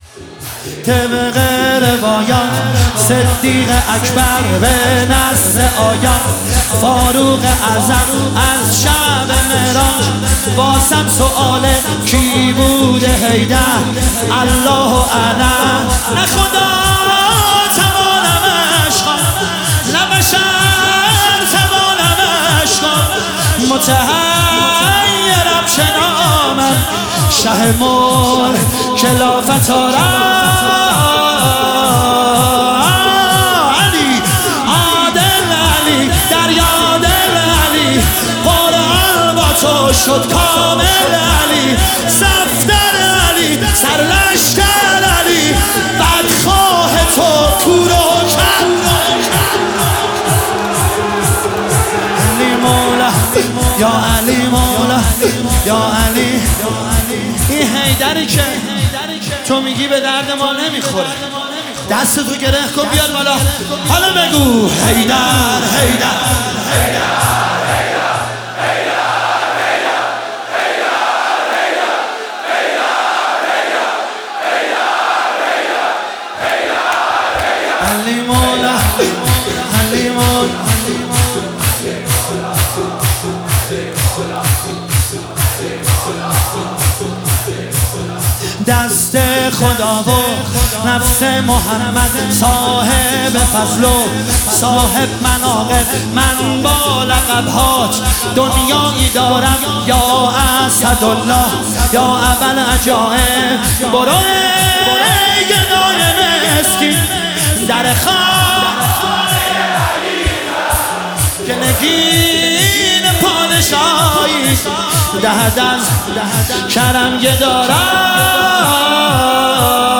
شور شب اول فاطمیه 1403